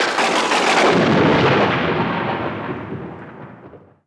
Wav World is the home of comedy desktop sounds.
thunderThunder sound
A big rumble.
thunder.wav